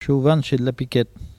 Localisation Saint-Hilaire-de-Riez
Langue Maraîchin
Catégorie Locution